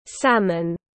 Màu cam cá hồi tiếng anh gọi là salmon, phiên âm tiếng anh đọc là /´sæmən/.